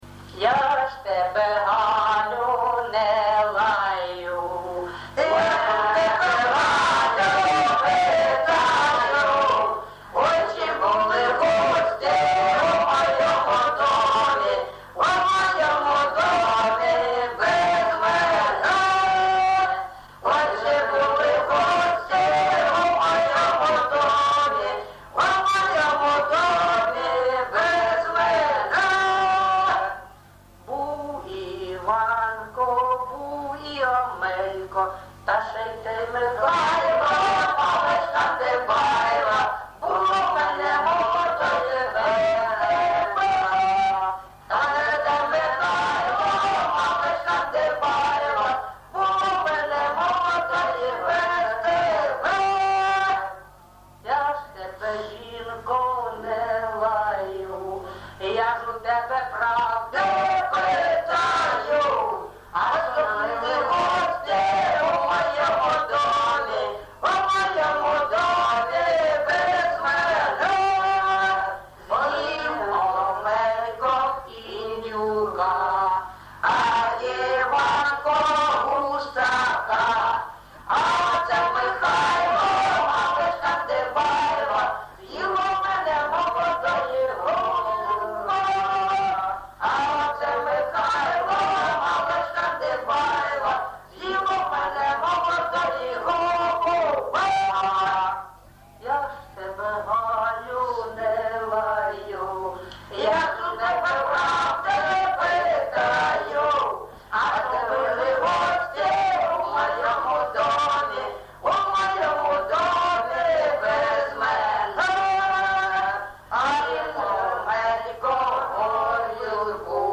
ЖанрЖартівливі
Місце записум. Єнакієве, Горлівський район, Донецька обл., Україна, Слобожанщина